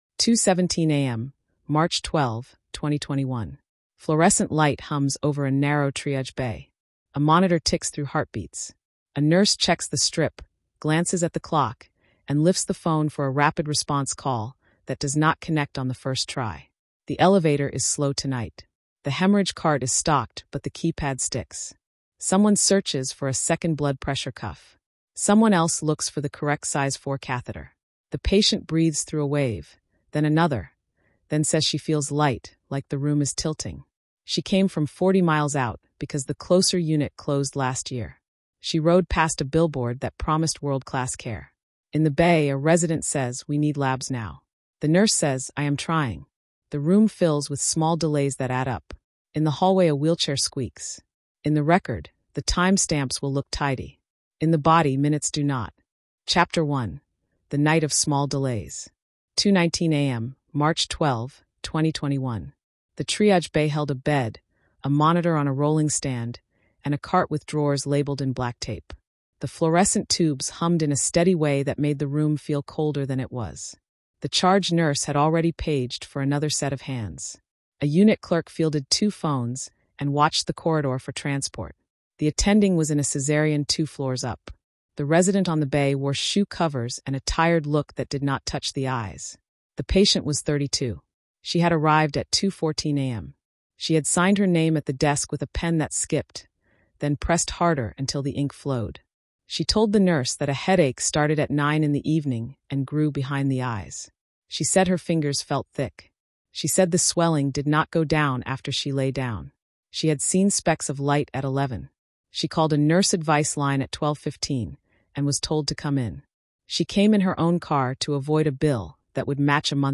This investigation follows that night step by step and then pulls the lens wider. Through inspection reports, complaint logs, and interviews with nurses, doulas, paramedics, and policy leaders, the story shows how risk builds in quiet ways.